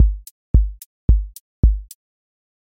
QA Test — four on floor
four on floor QA Listening Test house Template: four_on_floor April 17, 2026 ← Back to all listening tests Audio four on floor
voice_kick_808 voice_hat_rimshot